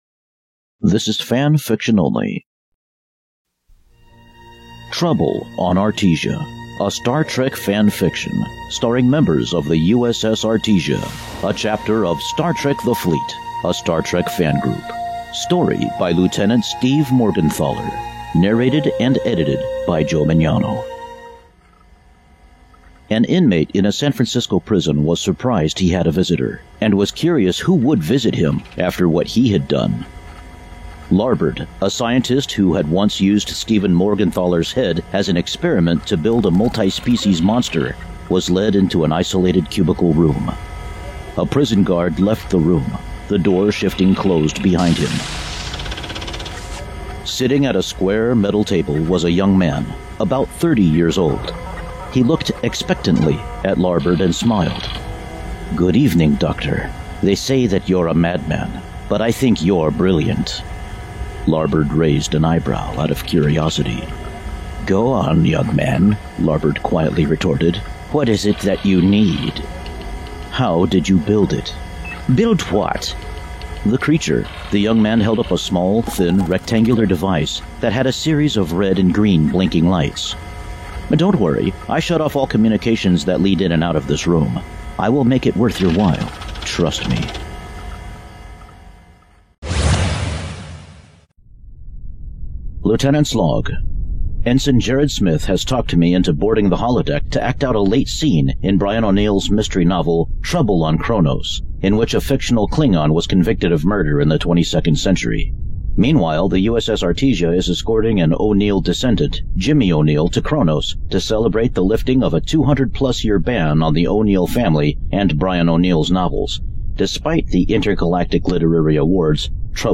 Audio Books/Drama Author(s